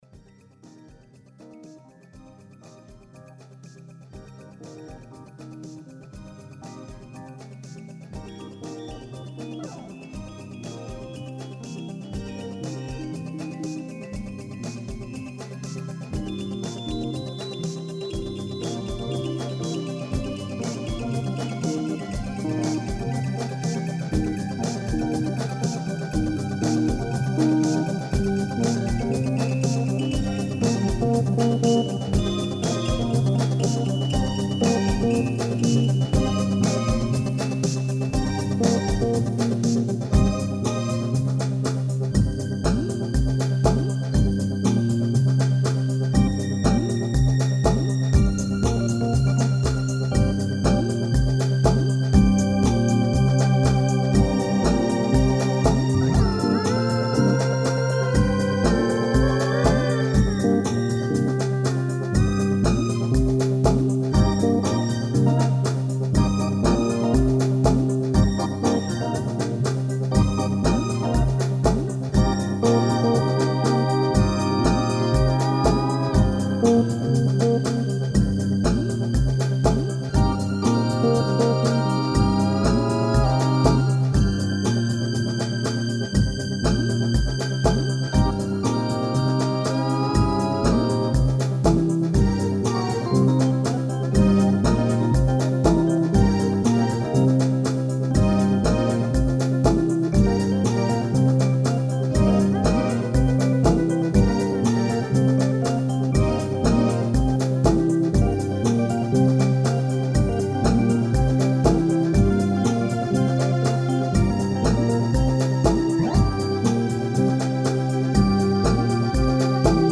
Tempo: 60 bpm / 25.10.2015